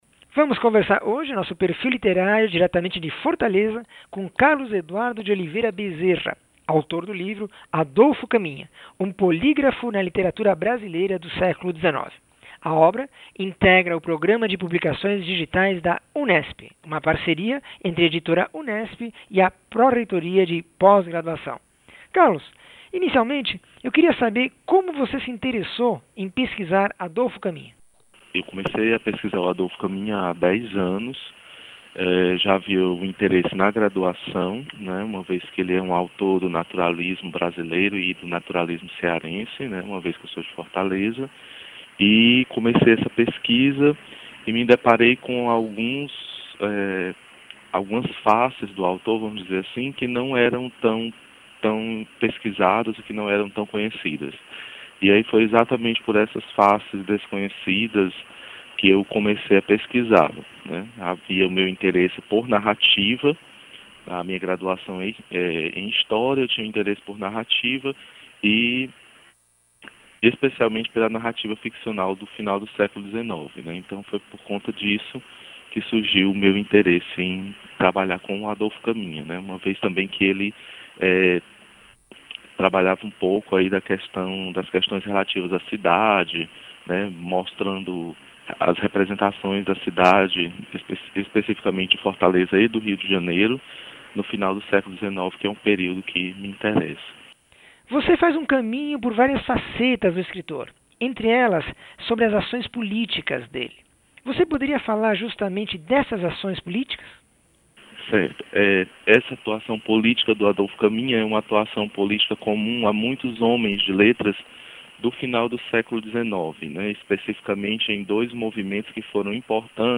entrevista 609